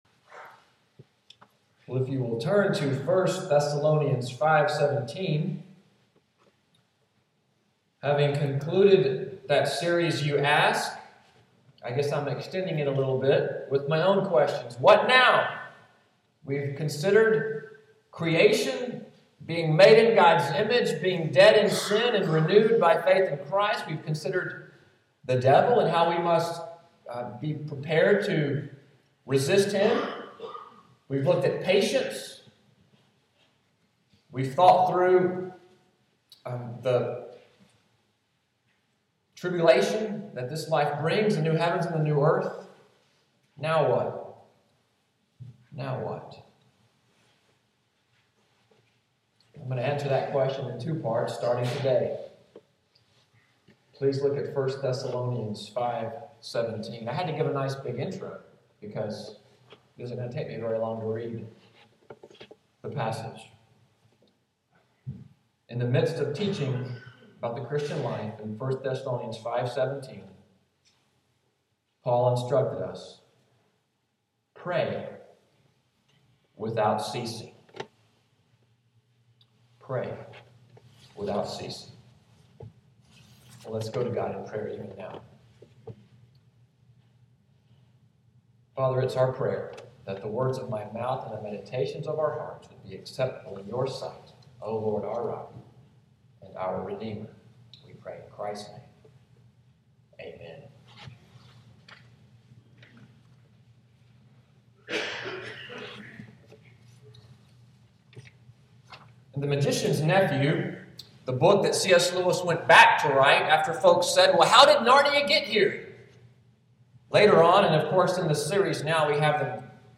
Audio of the sermon, “Now What?” [Part I: Let Us Pray], September 29, 2013